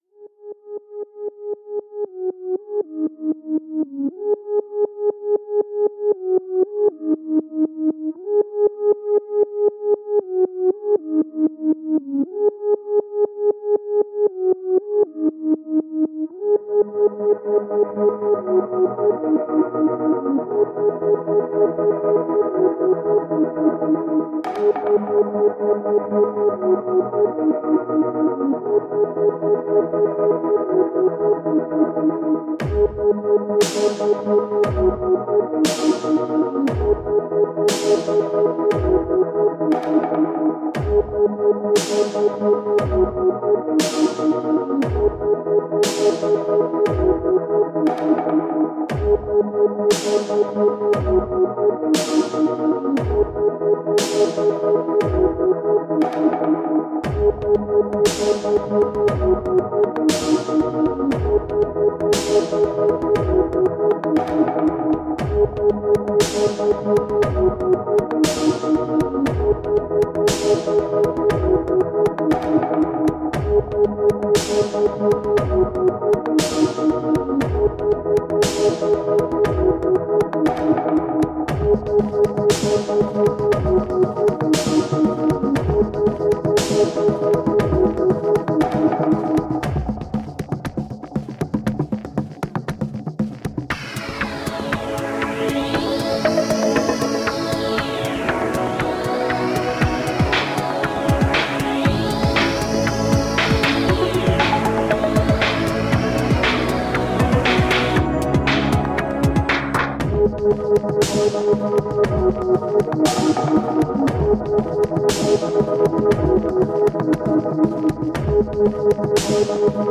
Electronica with a heart.
Tagged as: Electronica, Techno, Hard Electronic, Industrial